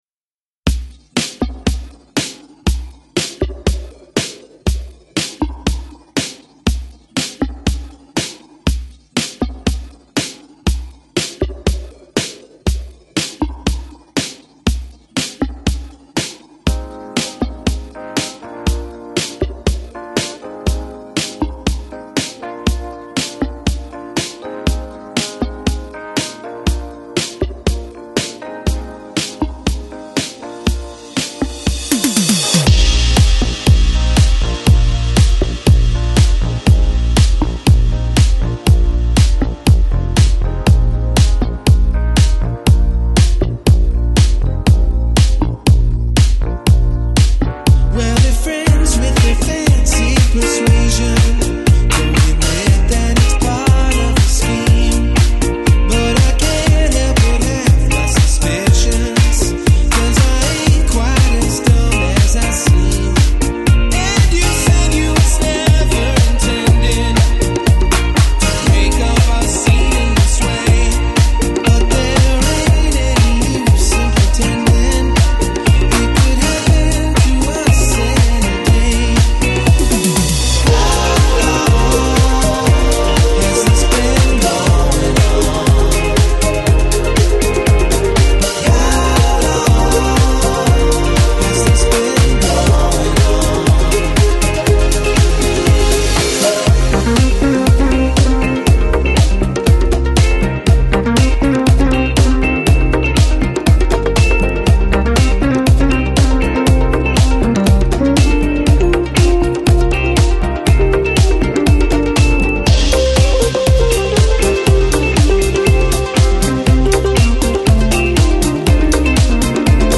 Жанр: Lounge, Chill Out, Downtempo, Jazz